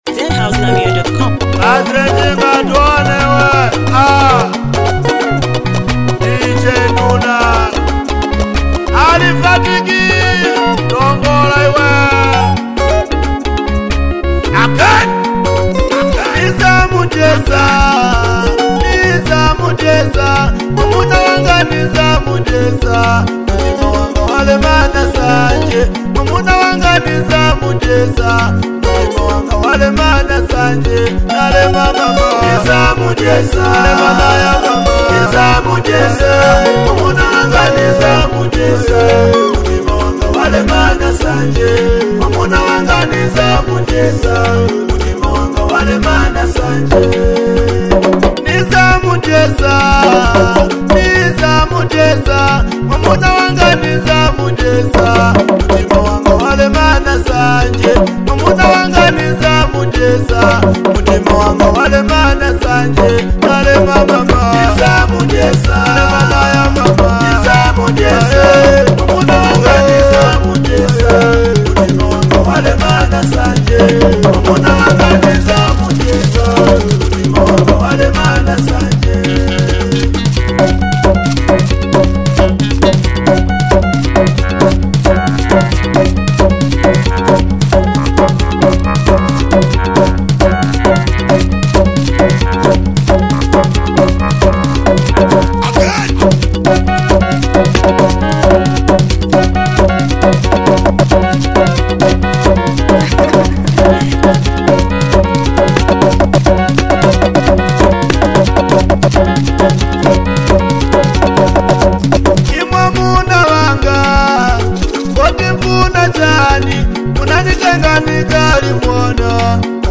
a provocative anthem laced with sharp storytelling.
With raw lyrics, cultural undertones, and heavy beats